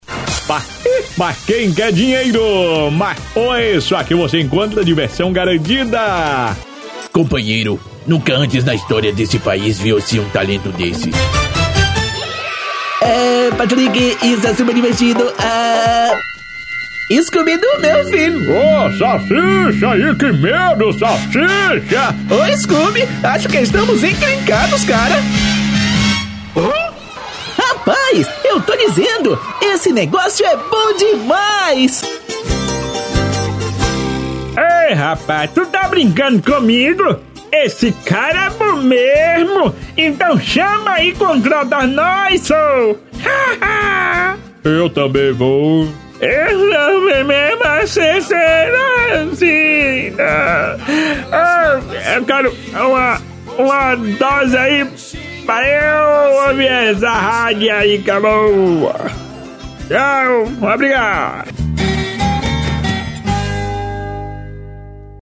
Spot Comercial
Impacto
Animada
Caricata